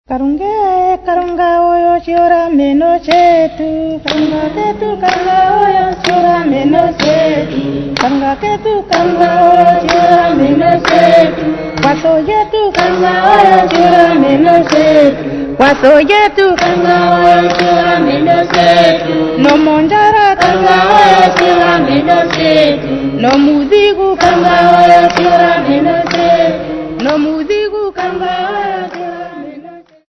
4 Men and 4 Women
Choral music
Field recordings
Africa Namibia Walvis Bay f-sx
Indigenous music.
96000Hz 24Bit Stereo